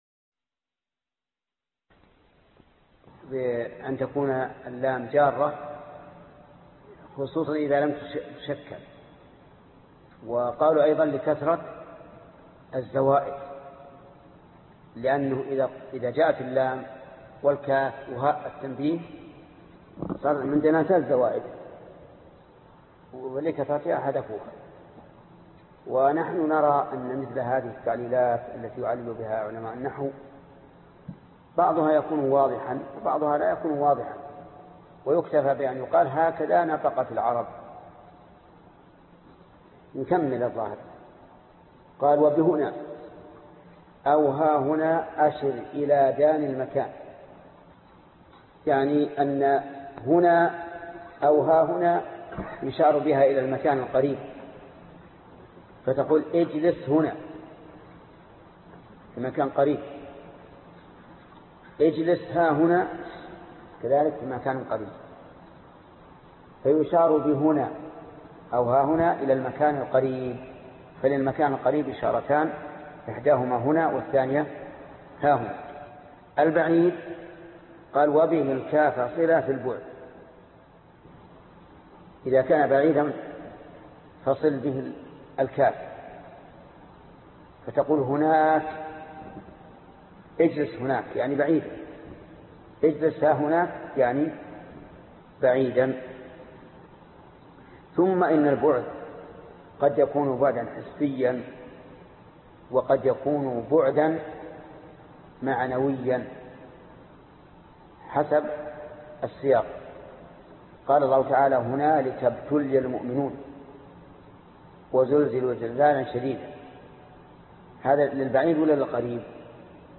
الدرس 64 ( شرح الفية بن مالك ) - فضيلة الشيخ محمد بن صالح العثيمين رحمه الله